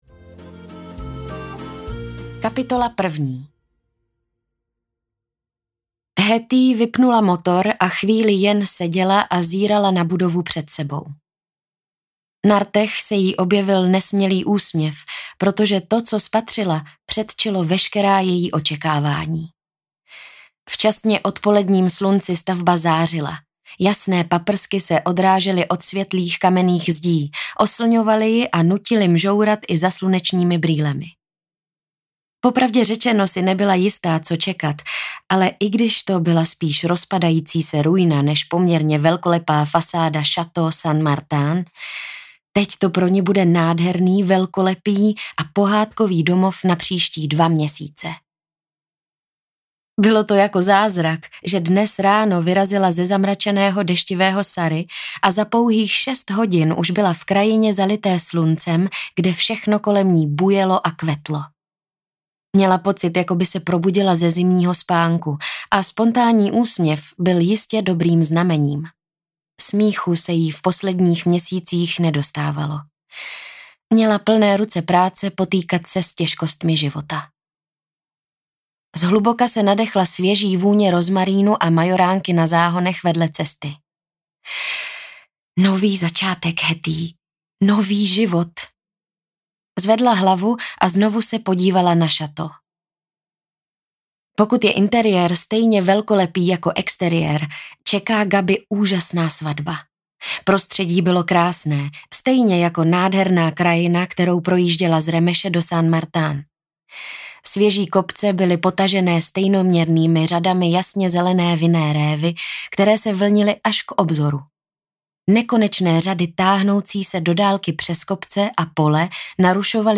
Vinice ve Francii audiokniha
Ukázka z knihy